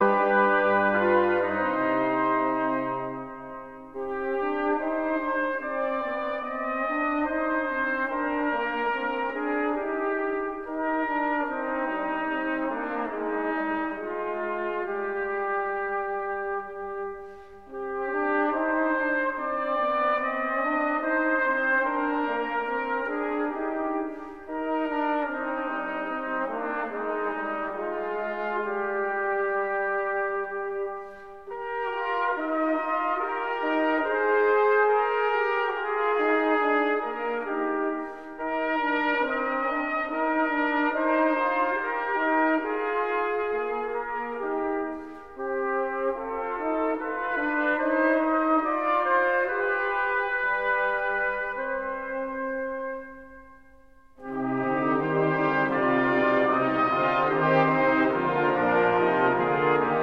Choräle & Heilslieder